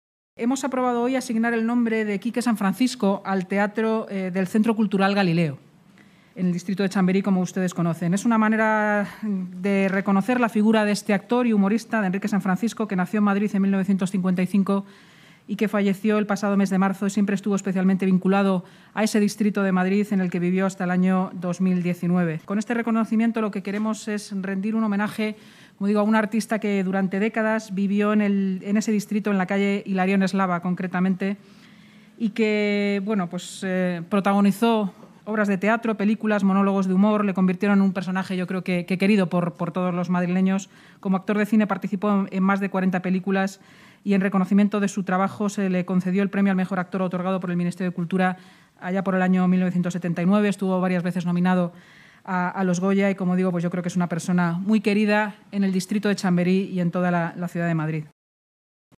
Nueva ventana:Inmaculada Sanz, portavoz municipal